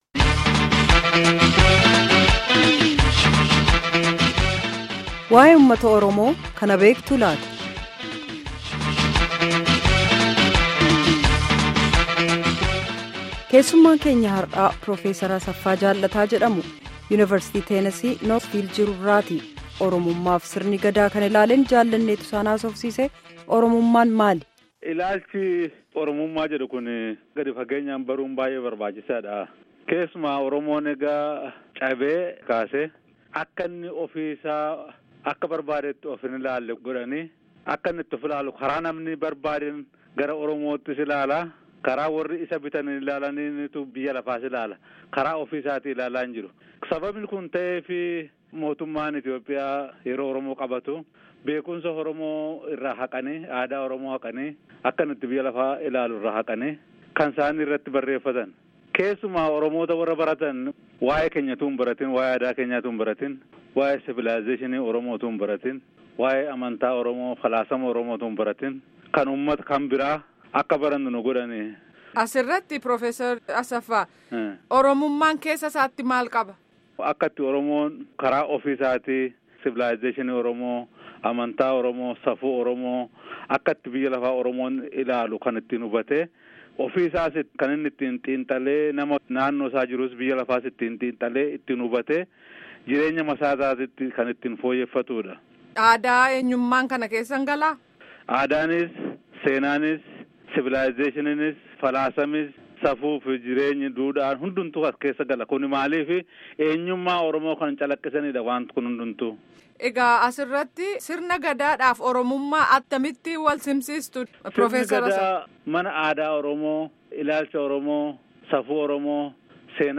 Gaaffii fi deebii guutuu armaa gadiitti caqasaa